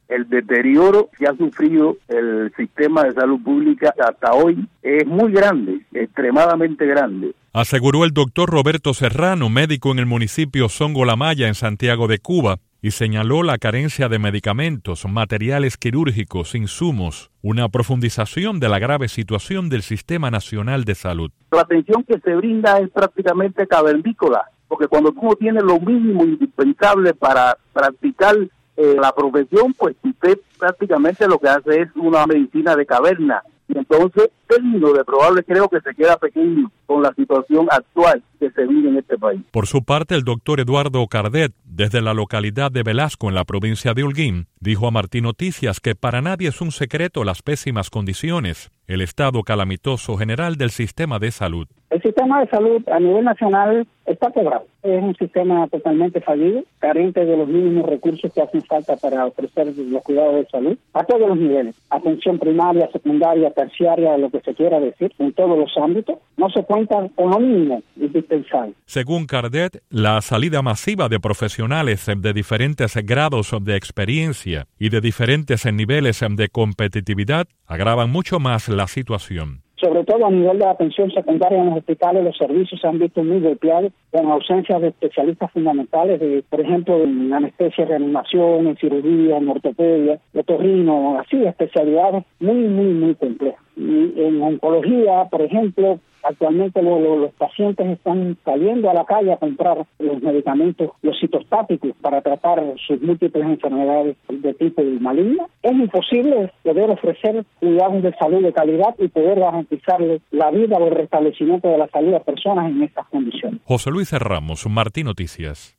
"La atención que se brinda es prácticamente cavernícola", dos médicos alertan sobre agudización de crisis de Salud Pública en Cuba